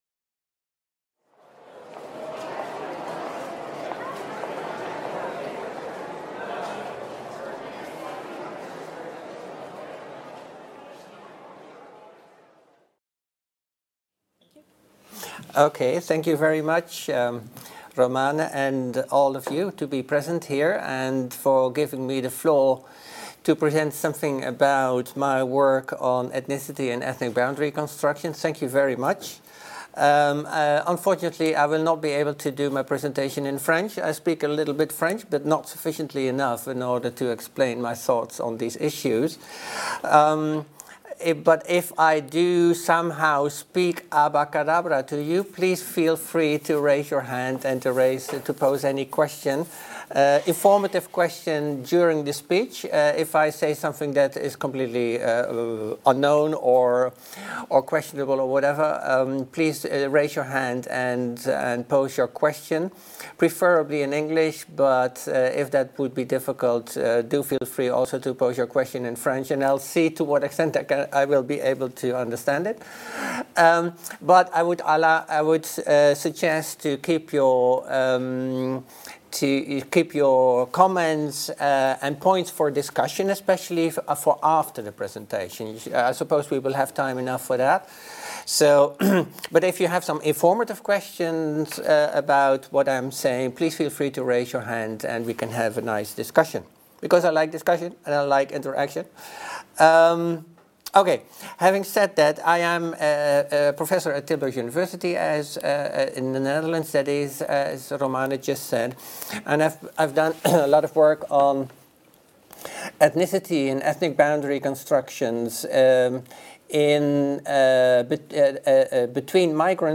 Séminaire diffusé en streaming de 14h30 à 16h30.